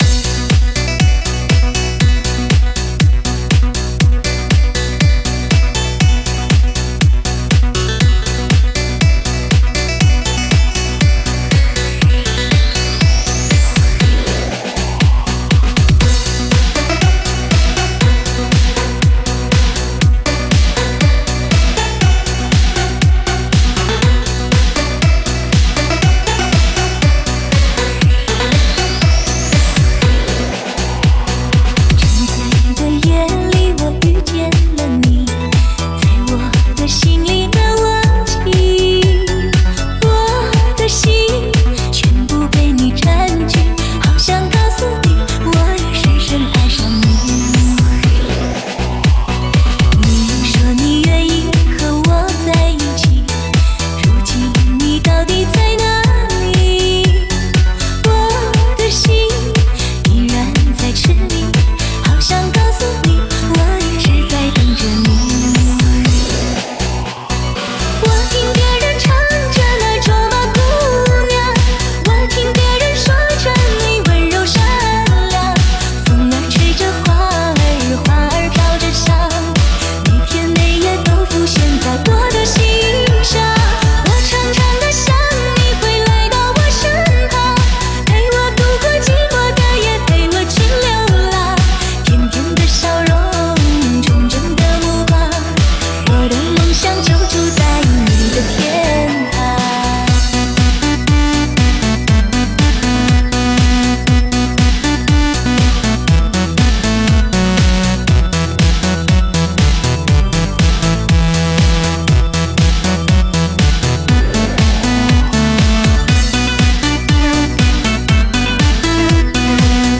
新世纪DJ舞曲
以天籁的声线，倾情的演绎，诉说人间的真情，在强劲的节奏中让大家体验非同凡响音效！